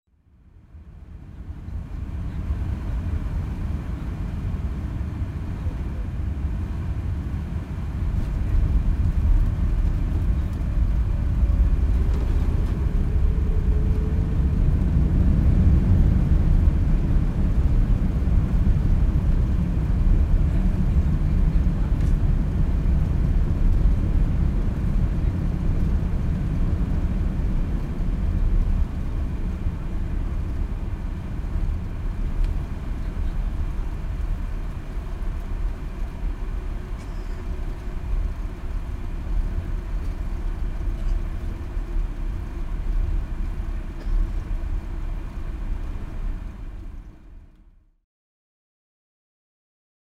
Gemafreie Sounds: Flughafen
mf_SE-6670-airplane_cabin_landing.mp3